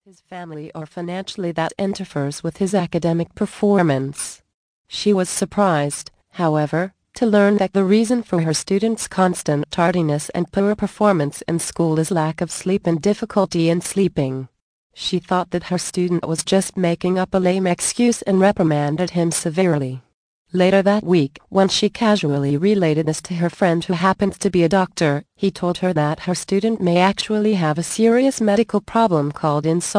The Magic of Sleep audio book Vol. 13 of 14, 70 min.